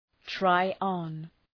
Shkrimi fonetik {‘traıɒn}